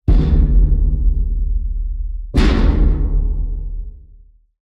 Metal_door_impact_resonance.wav
Ce bruit de lourde porte métallique fonctionne bien et le lieu y est pour quelque chose.
Metal_impact_resonating.wav